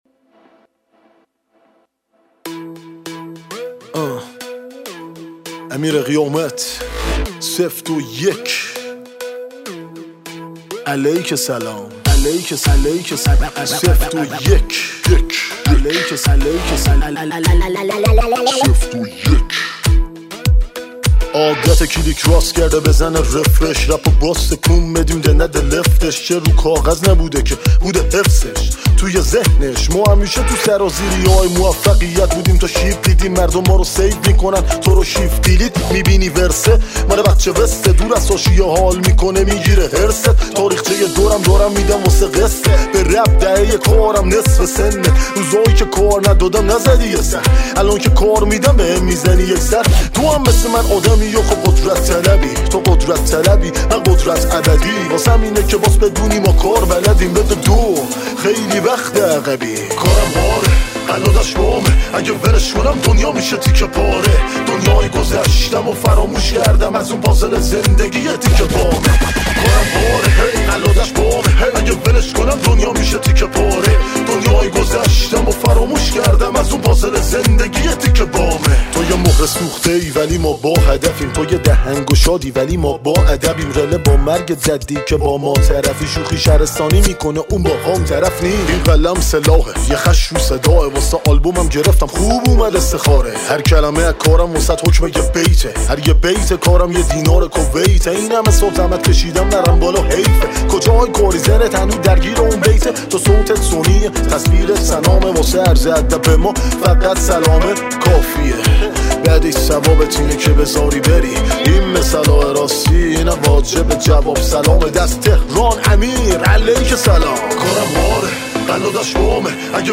تک اهنگ ایرانی